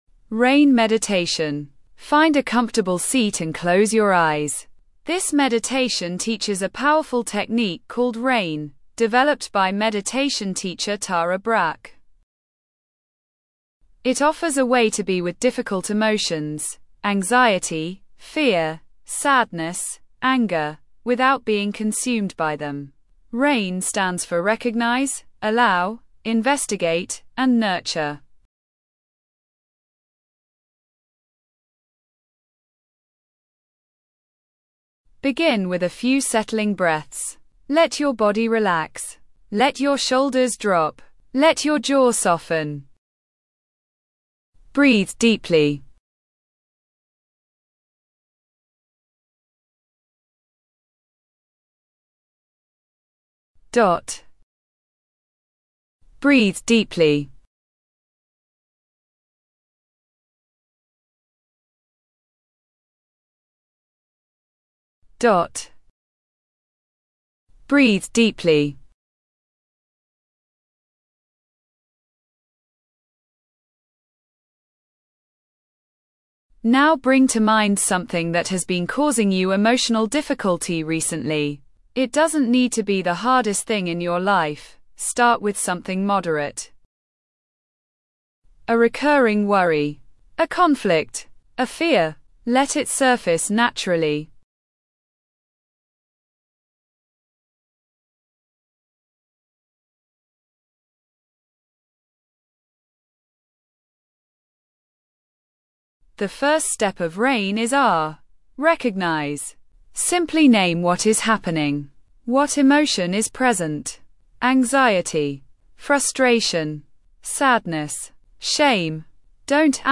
RAIN Meditation